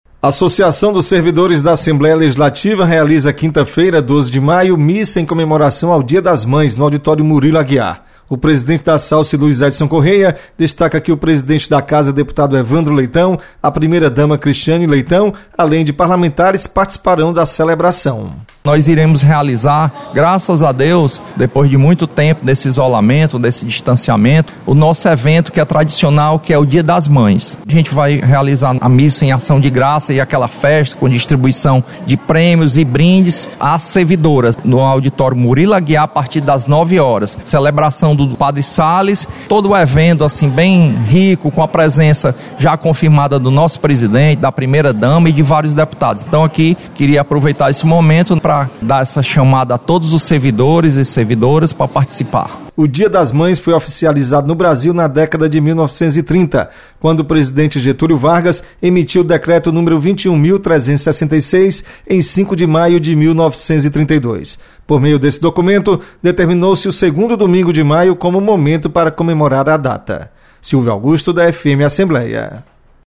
Assalce realiza missa em comemoração ao Dia das Mães no auditório Murilo Aguiar.